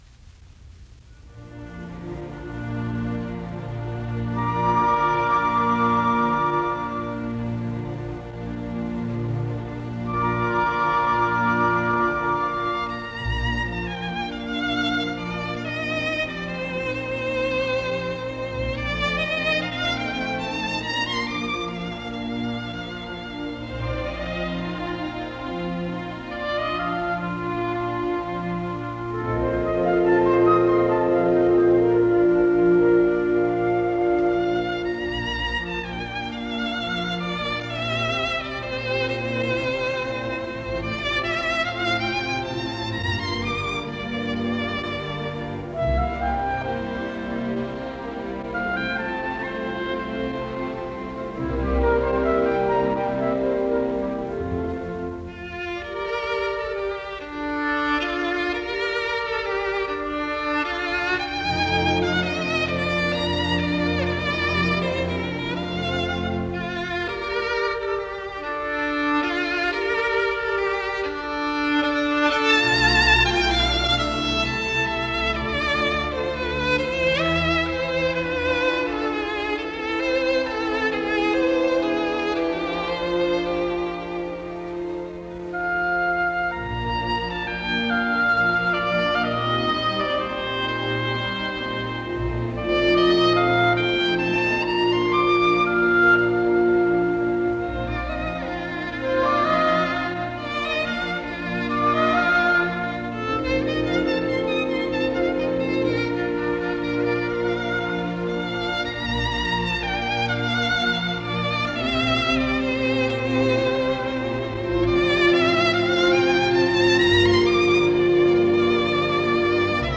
내림 나장조, 6/8박자, 3부 형식이다. 1악장의 정열적인 분위기와는 대조적으로, 온화하고 서정적인 뱃노래풍의 악장이다. 코다에서는 독주 바이올린의 플라젤렛(flageolet)과 클라리넷의 유니슨(unison)에 의한 아르페지오가 환상적인 음향을 자아낸다.